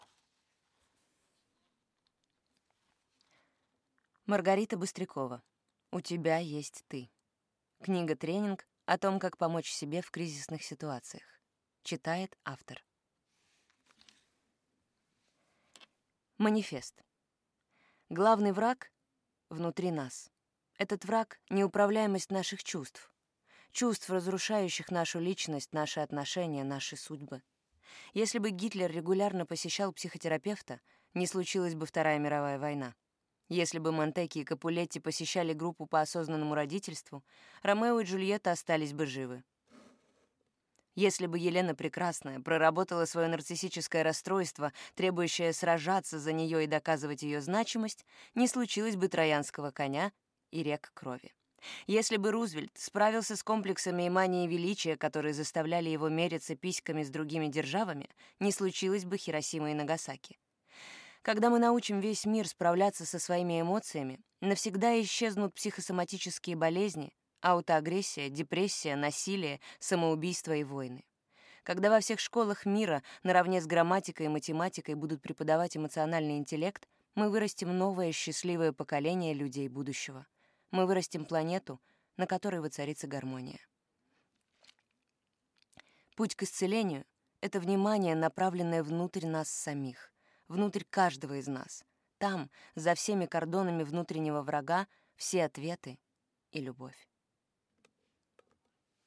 Аудиокнига У тебя есть ты. Медитация 13. Практика проживания горя | Библиотека аудиокниг